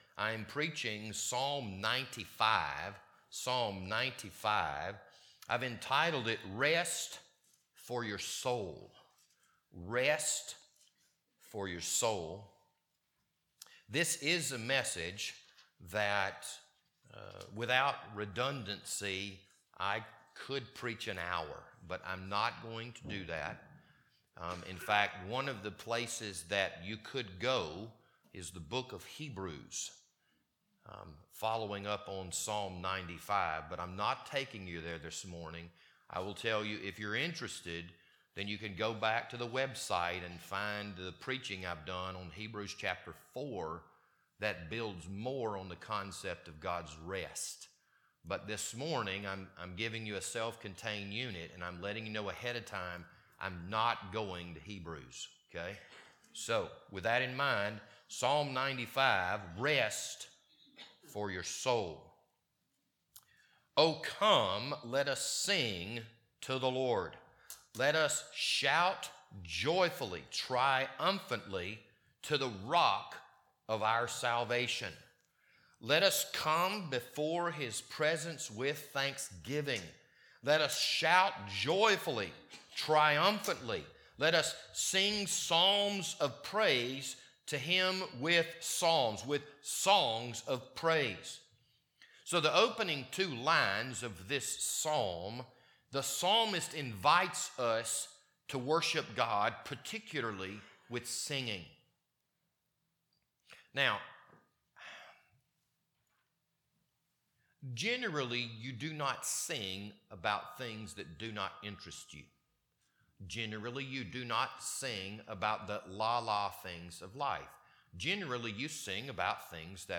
This Sunday morning sermon was recorded on February 26th, 2023.